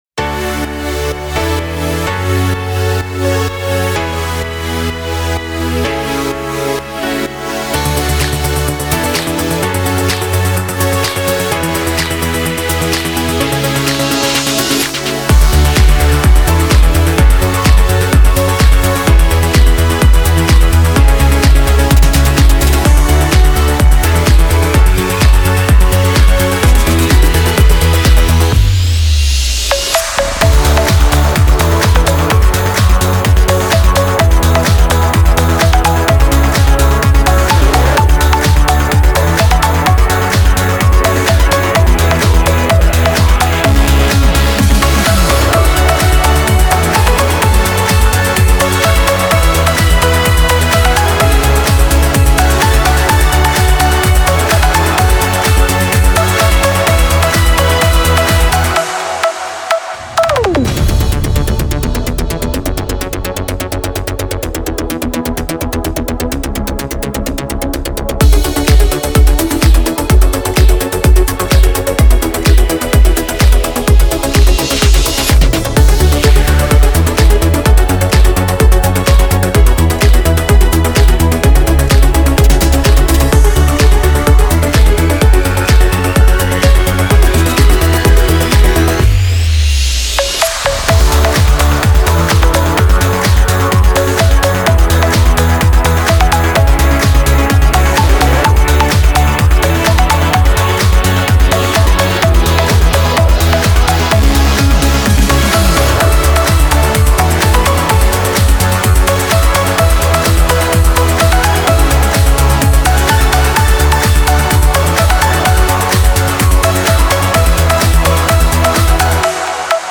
Жанр: Electronic